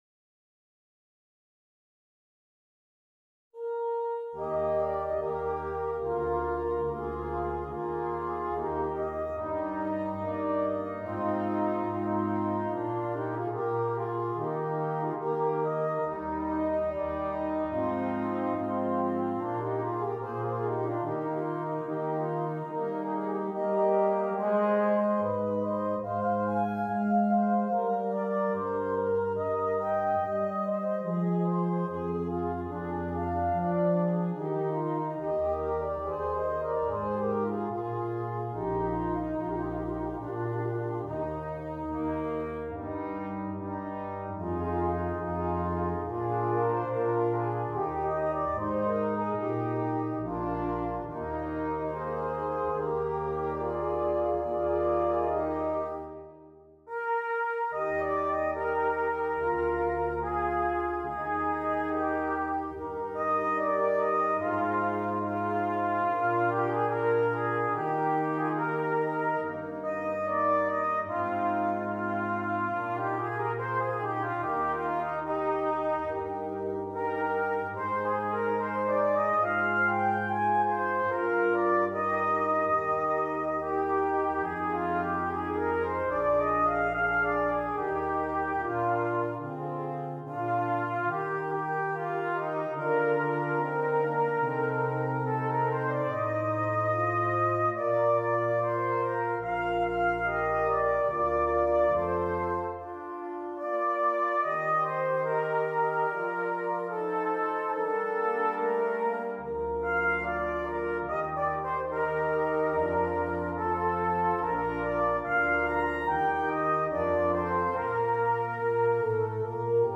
Brass Quintet and Solo Trumpet